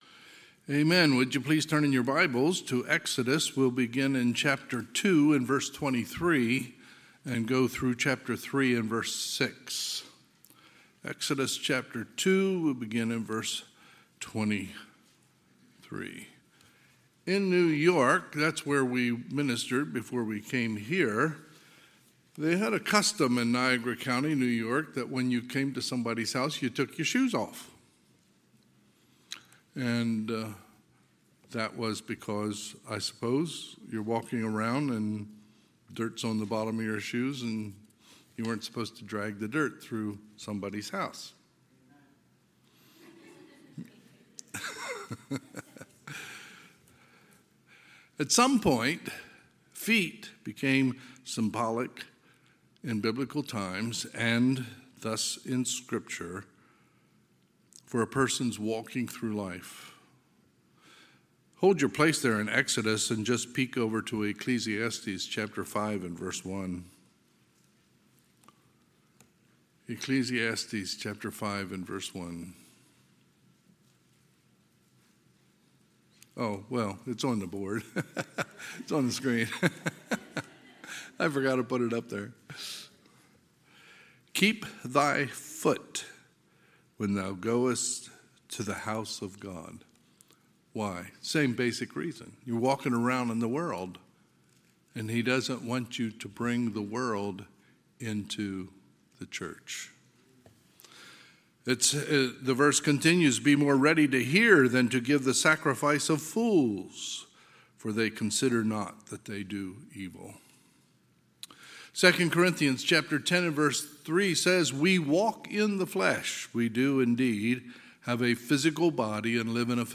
2023 Sermons admin Exodus 2:23 – 3:6